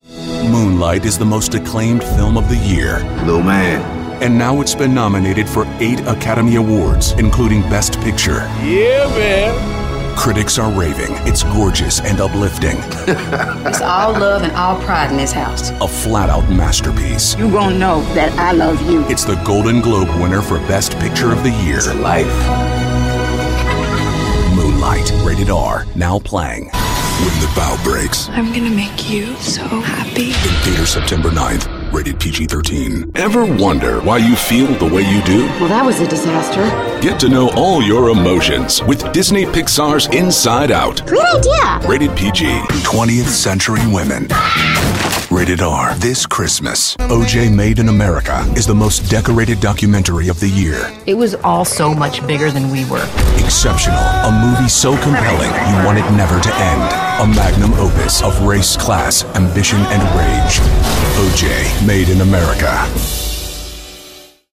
chicago : voiceover : commercial : men